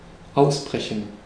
Ääntäminen
IPA: [ˈʔaʊ̯sbʁɛçn̩]